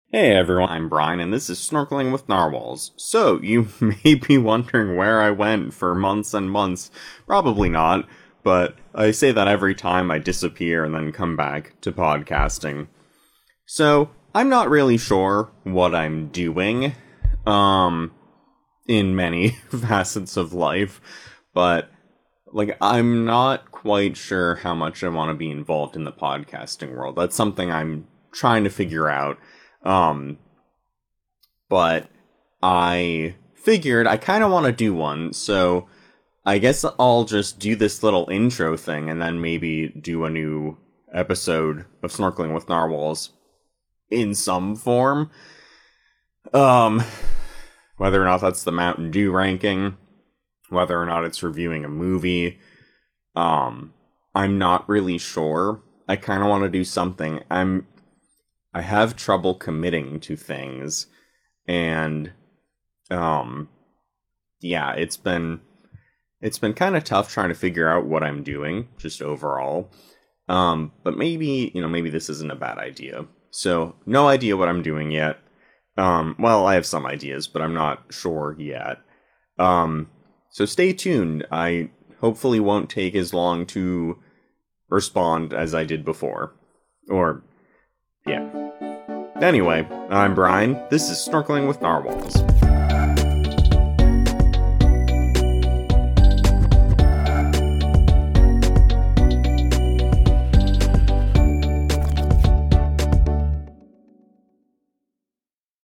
So I'm just here talking a little about what's been going on with me. It's pretty unedited, just me talking.